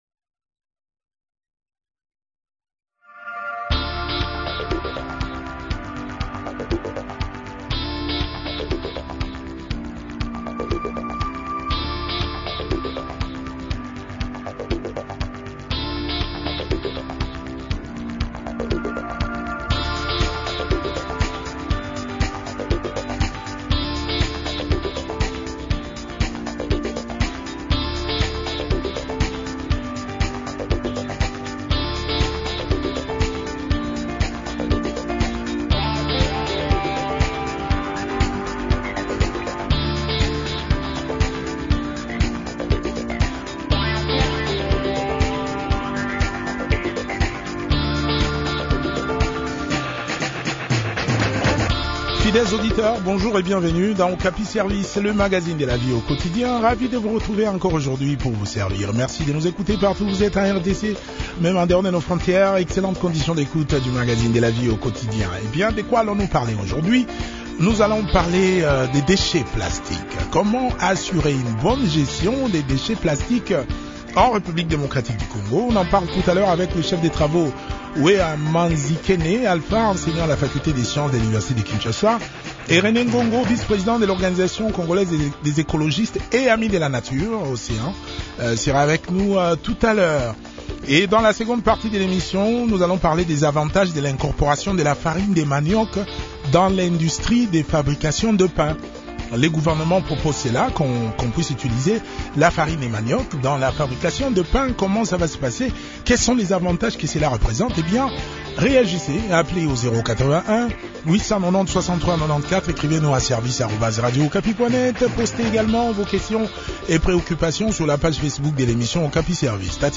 s’entretient sur ce sujet